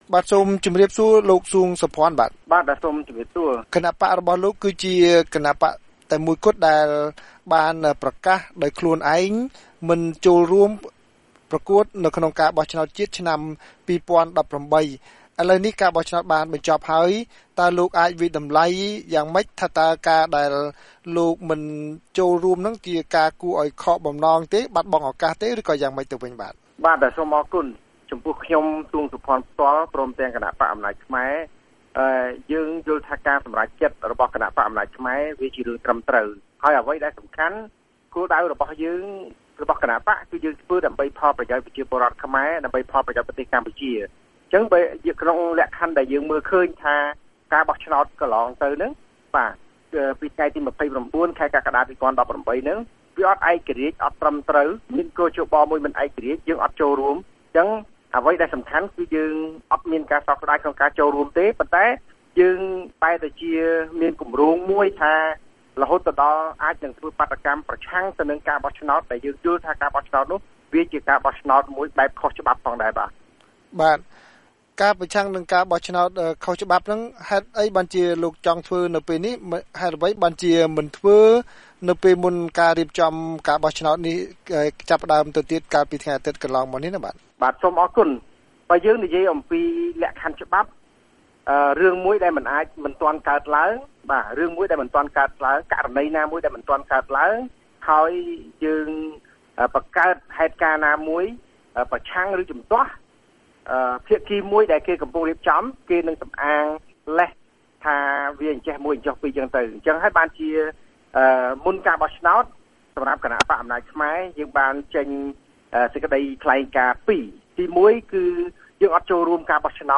បទសម្ភាសន៍VOA៖ បន្ទាប់ពីពហិការបក្សអំណាចខ្មែរស្វែងរកការរួបរួមបក្សប្រឆាំង